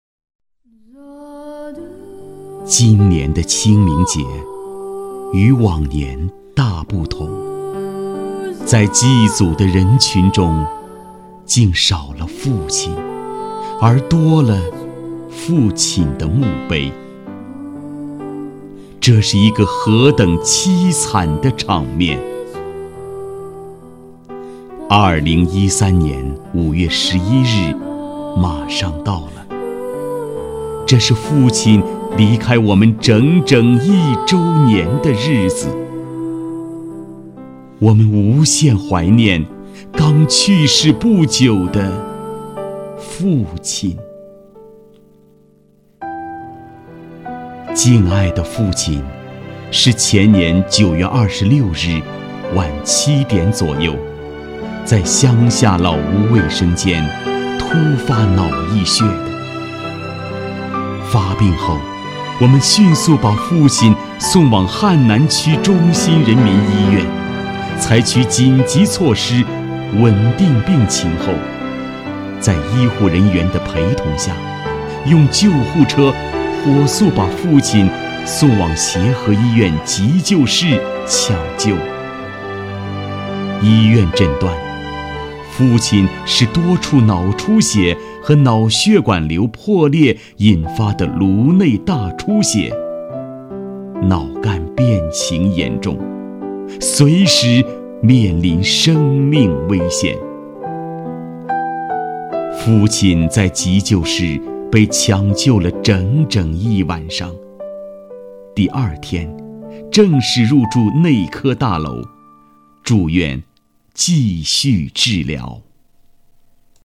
男国162_其他_旁白_清明节怀念父亲_伤感.mp3